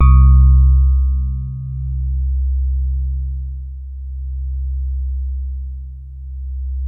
FINE SOFT C1.wav